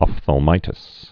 (ŏfthəl-mītĭs, -thăl-, ŏp-)